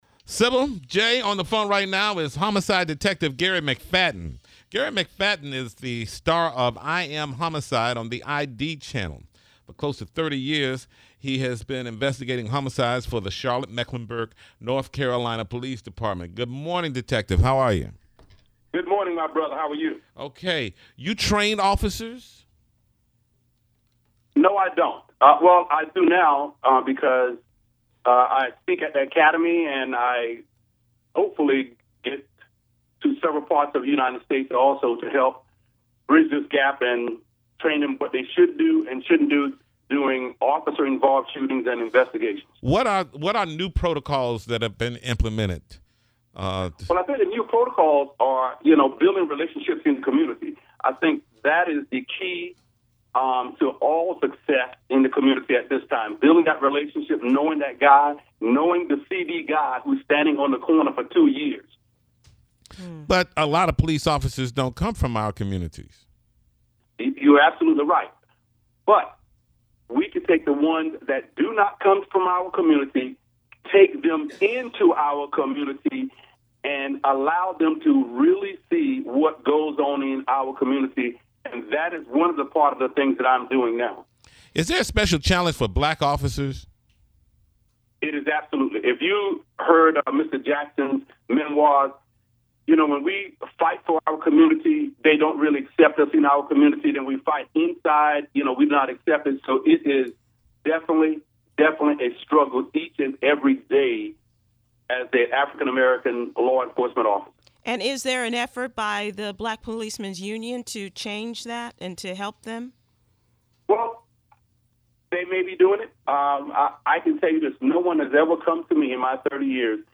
Listen to what challenges black police officers face when on duty and the rest of the interview above.